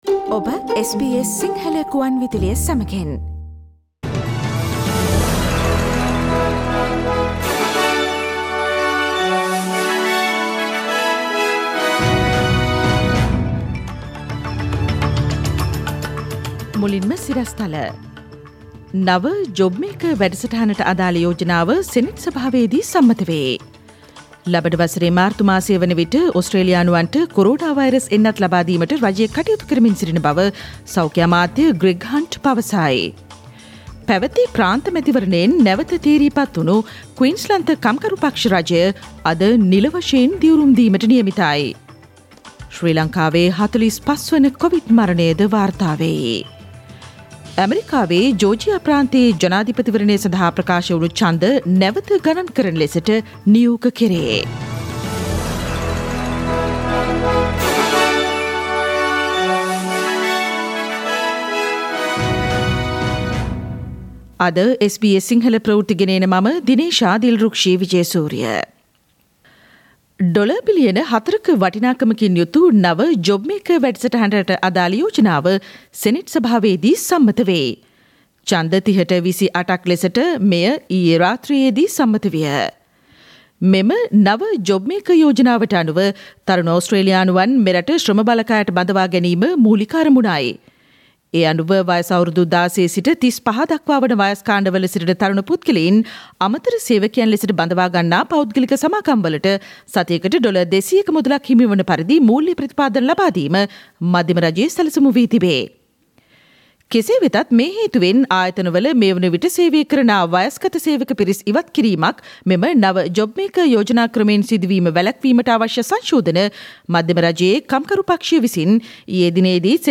Daily News bulletin of SBS Sinhala Service: Thursday 12 November 2020